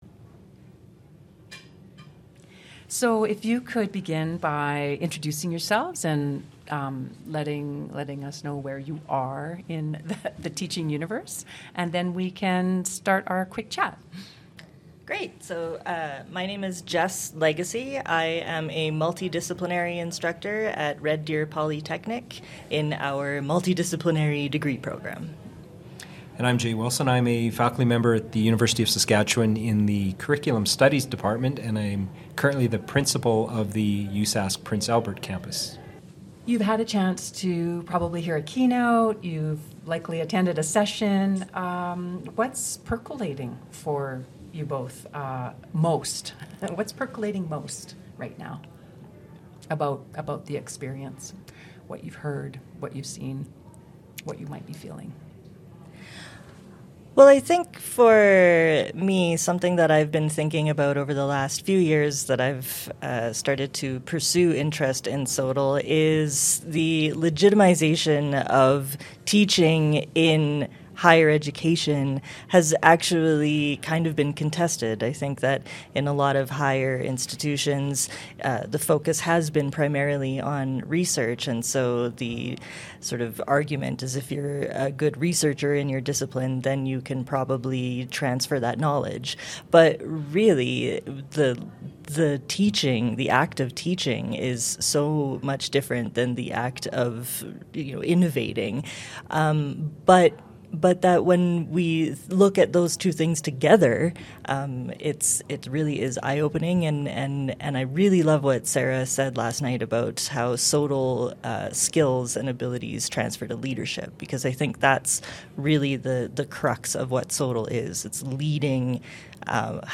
Interviewer (ivr)
The SOTL in 60+ podcast series consisted of ten episodes recorded during the 2024 Symposium for Scholarship of Teaching and Learning November 7-9, 2024 in Banff, Alberta, Canada.